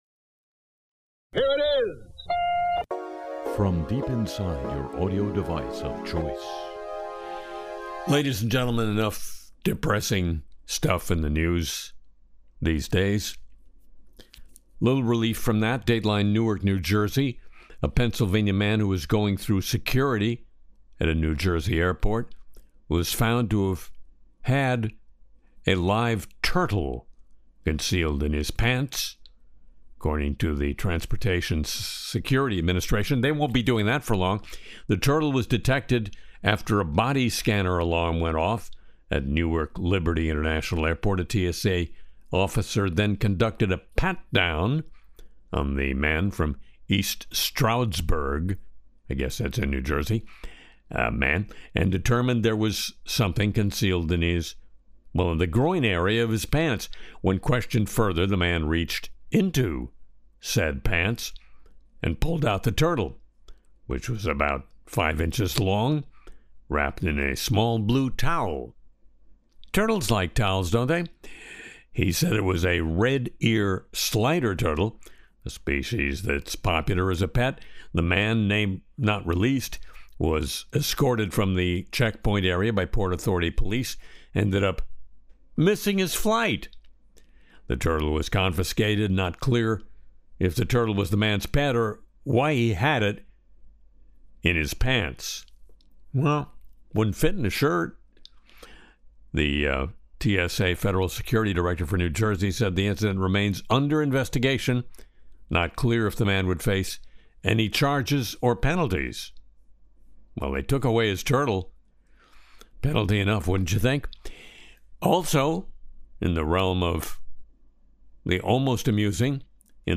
Vincent Price reads 'Casey At The Bat'